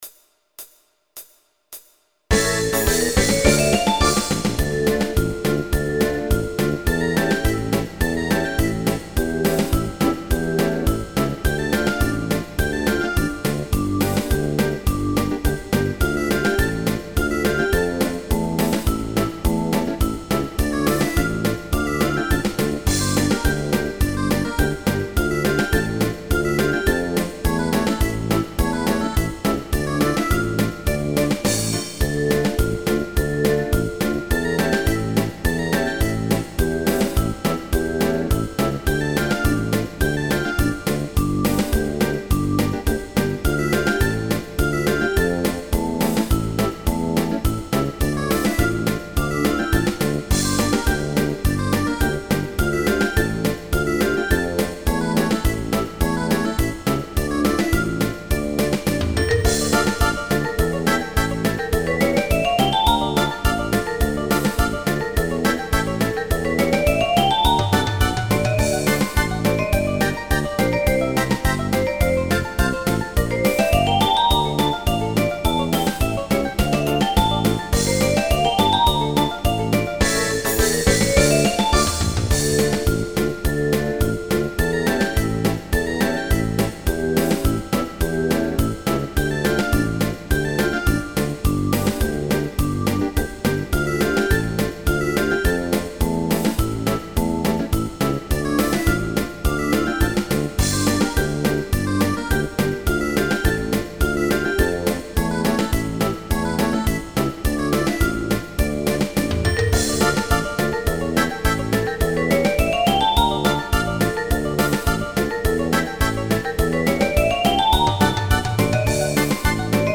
Детские